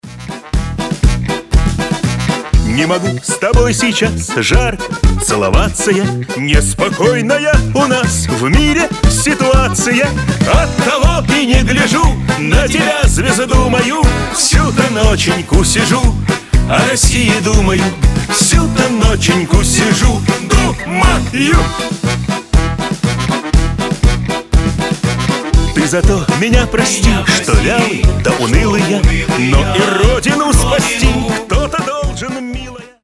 веселые
аккордеон
патриотические
военные